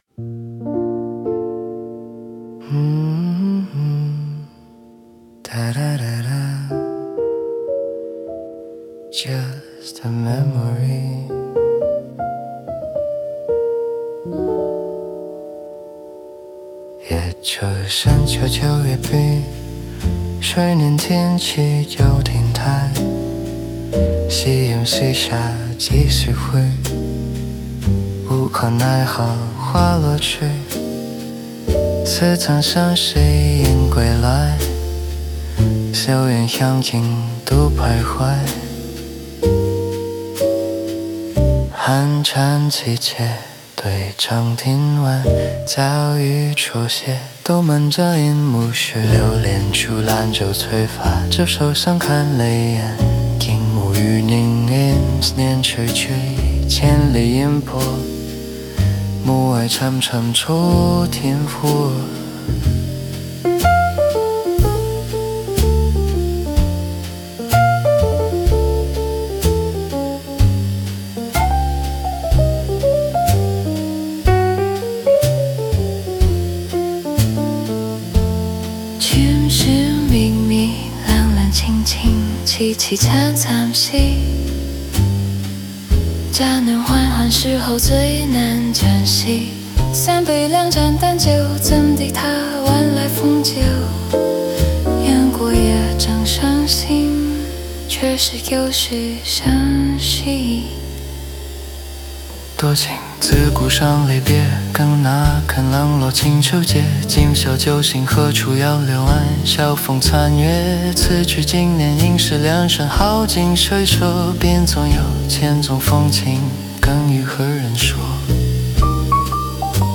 Cool Jazz Ballad × 古典詞的現代聲景
slow｜relaxed swing、rubato
直立鋼琴,原聲貝斯,刷鼓
Tags: 宋詞,爵士詩,鋼琴三重奏,慢板,60s類比,磁帶飽和,吟誦,離愁,文學音樂